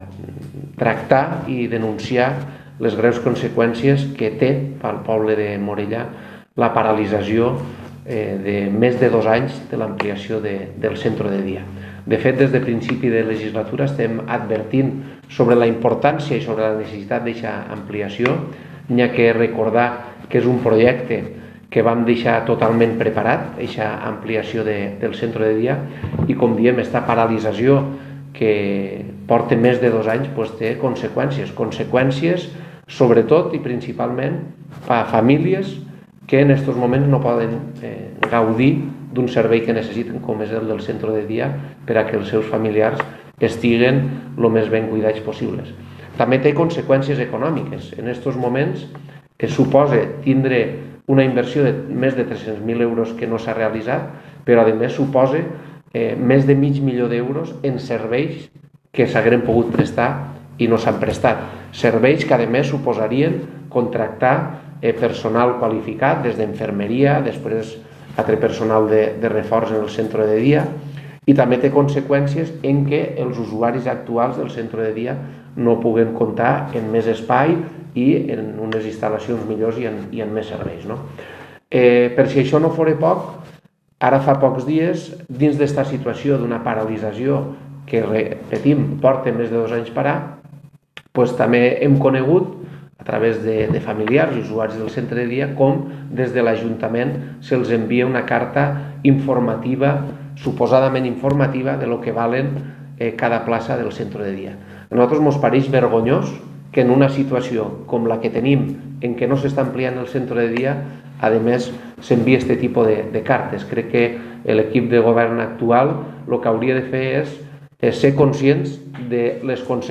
DECLARACIONS-RHAMSES-RIPOLLES-PARALITZACIO-OBRES-CENTRE-DE-DIA-MORELLA.m4a